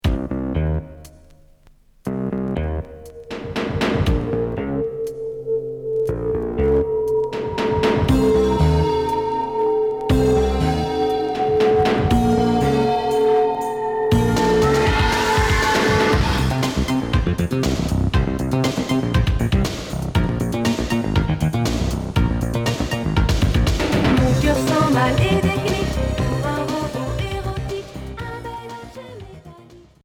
Cold wave indus